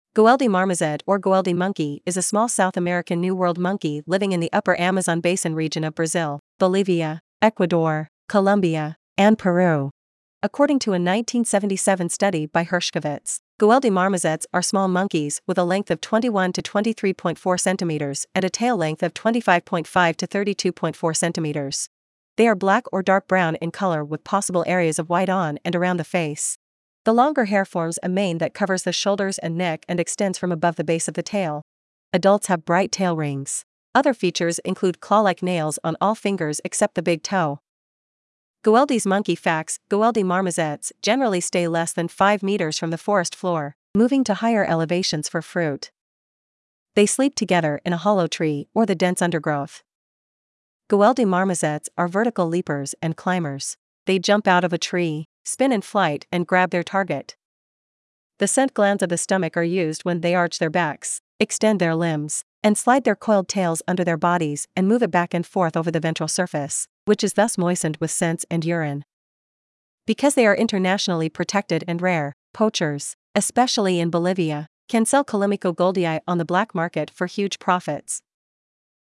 Goeldi's Monkey
Goeldis-Monkey.mp3